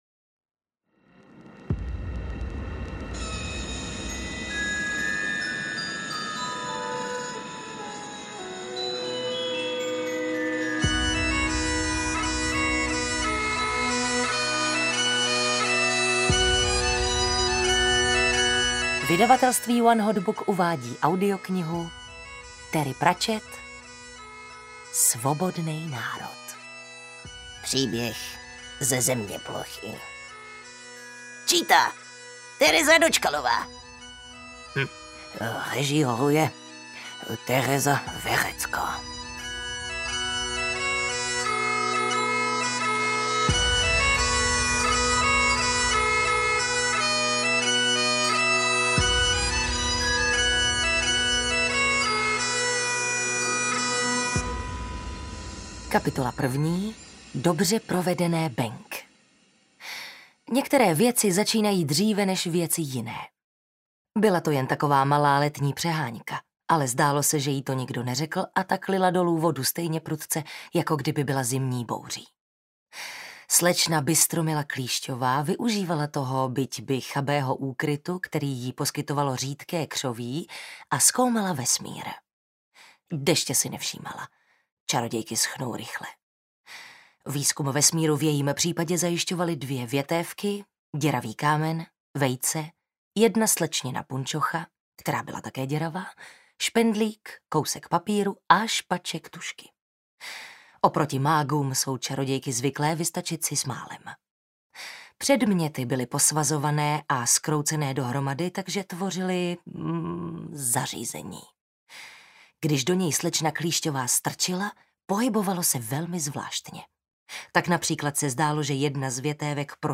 Klasická kvalita, nádherné nářečí Fíglů a úplně první knížka zeměplochy, ve které není Smrť.